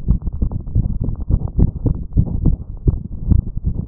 Chaotischer Rhythmus mit einem Herzgeräusch: Vorhofflimmern und Mitralinsuffizienz
Dog-AFib-MR-S3.wav